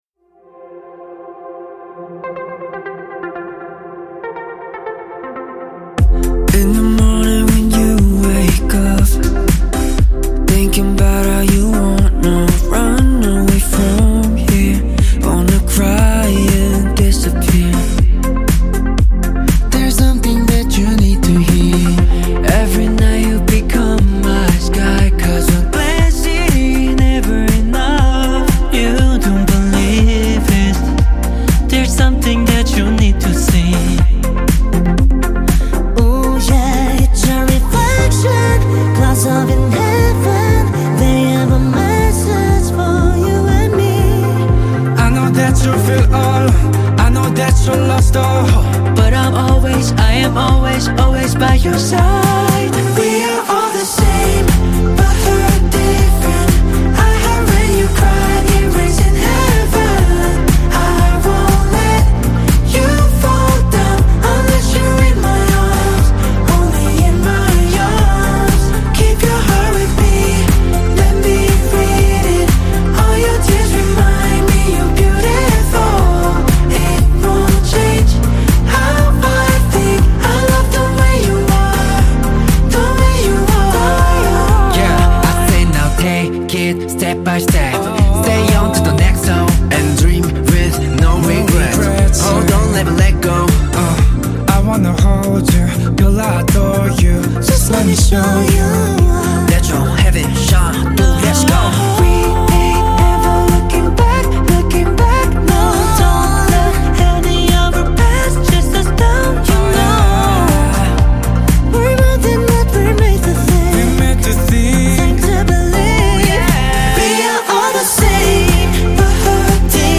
KPop Song